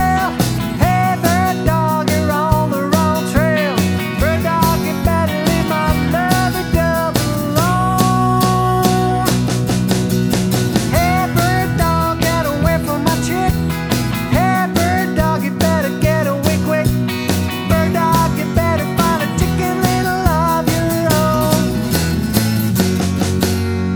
No Harmony Pop (1950s) 2:15 Buy £1.50